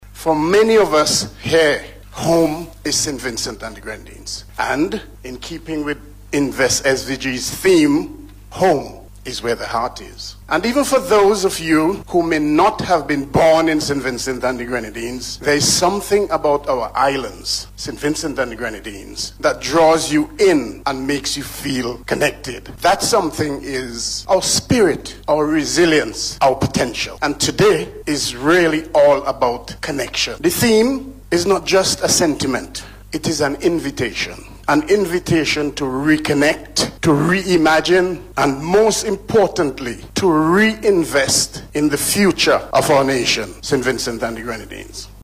Saint Vincent and the Grenadines High Commissioner to the United Kingdom His Excellency Brereton Horne, made the made the call, during his remarks at the opening of a Conference hosted by Invest SVG in London yesterday.